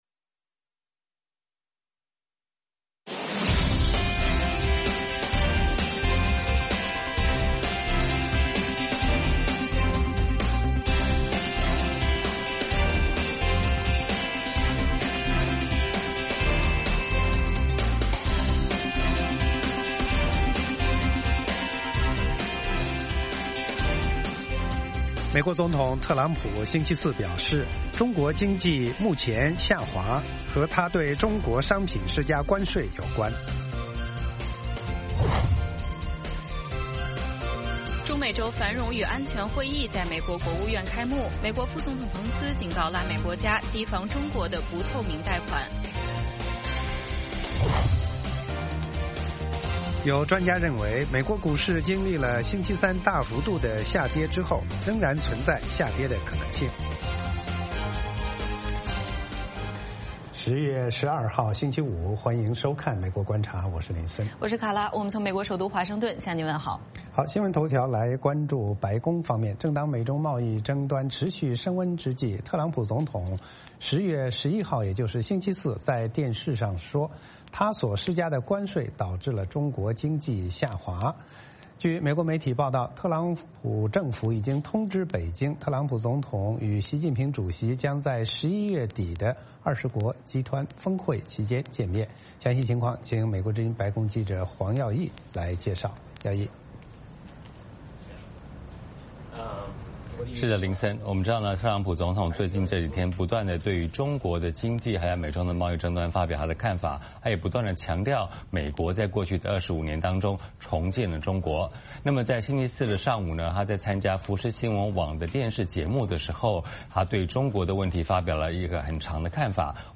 美国之音中文广播于北京时间早上8－9点重播《VOA卫视》节目(电视、广播同步播出)。
“VOA卫视 美国观察”掌握美国最重要的消息，深入解读美国选举，政治，经济，外交，人文，美中关系等全方位话题。节目邀请重量级嘉宾参与讨论。